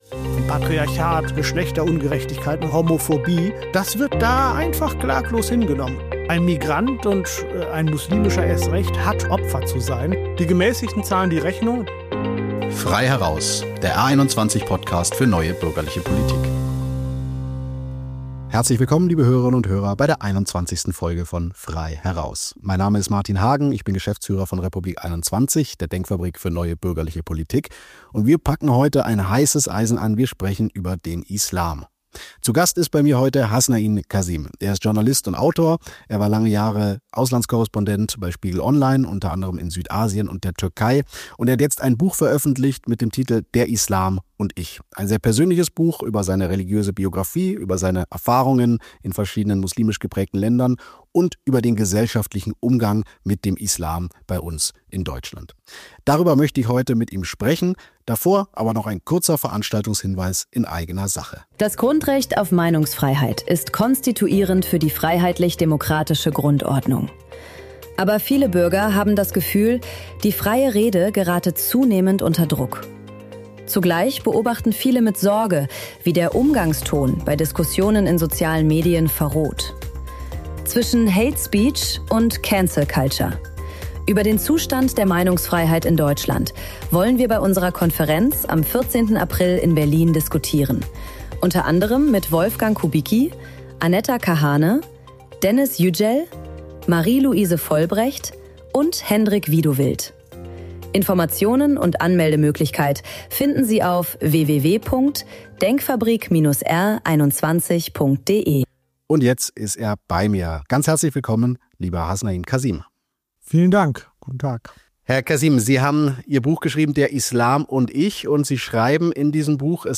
Mit R21-Geschäftsführer Martin Hagen spricht Kazim über sein neues Buch „Der Islam und ich“, über das Kopftuch an Schulen und die Vereinbarkeit von Islam und Demokratie.